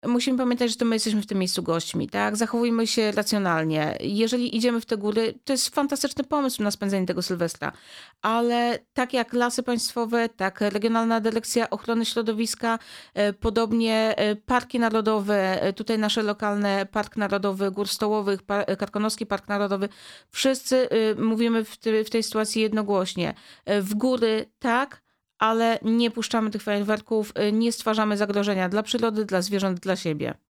Zbliżający się Sylwester, rosnąca popularność górskich wędrówek, potrzeba ochrony przyrody oraz 5. edycja akcji „Choinka dla Życia” – to główne tematy rozmowy w studiu Radia Rodzina.